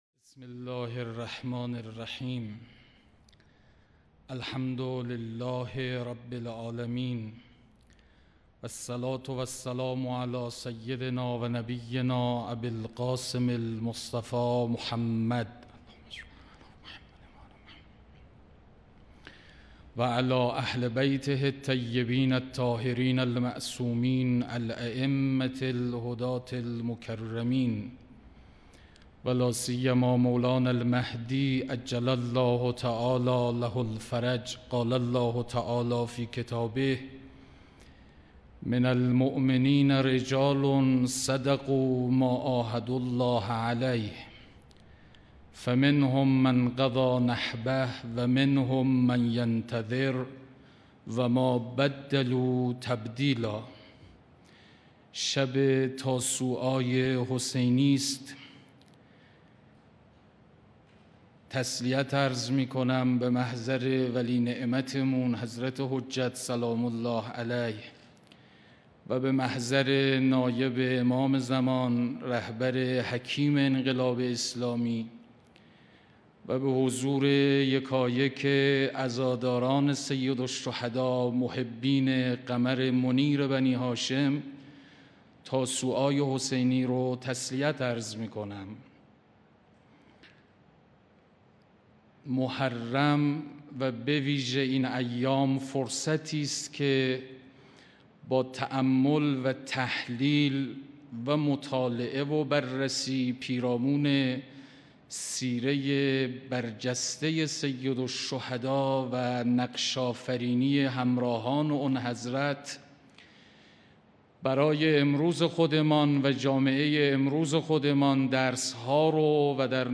مراسم عزاداری شب تاسوعای حسینی
صوت / سخنرانی